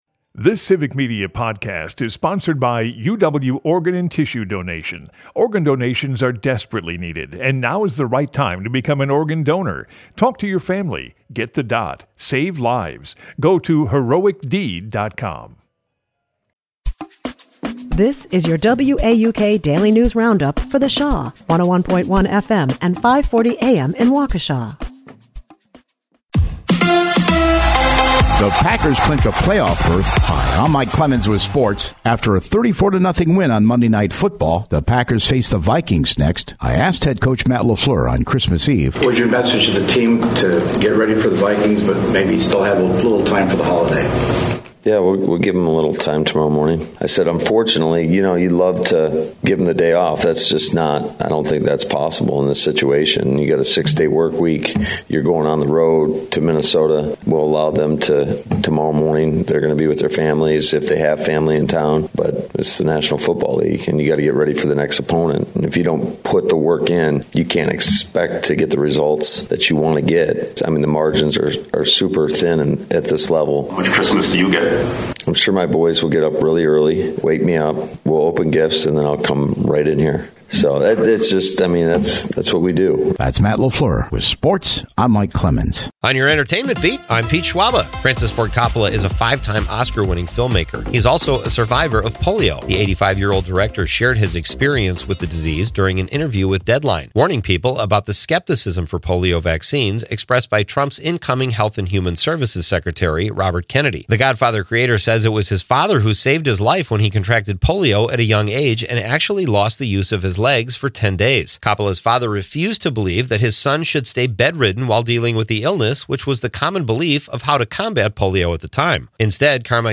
The WAUK Daily News Roundup has your state and local news, weather, and sports for Milwaukee, delivered as a podcast every weekday at 9 a.m. Stay on top of your local news and tune in to your community!